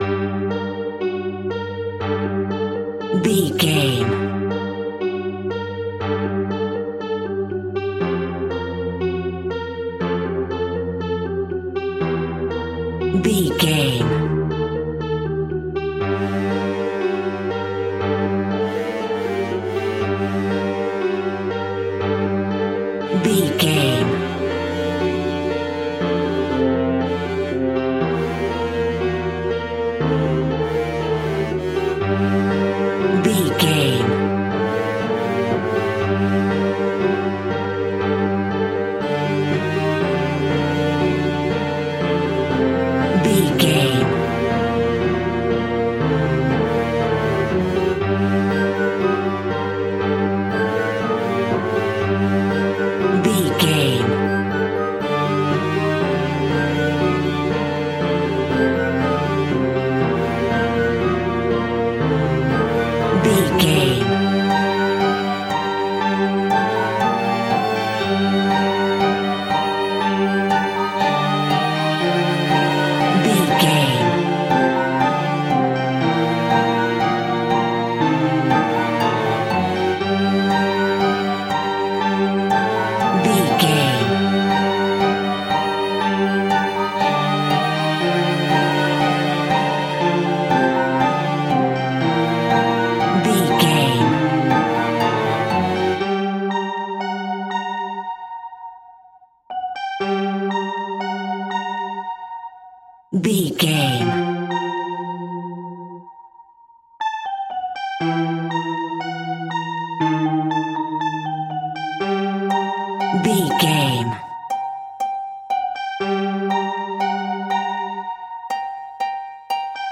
Piano Fear Music.
In-crescendo
Aeolian/Minor
scary
tension
ominous
dark
suspense
haunting
eerie
strings
synth
pads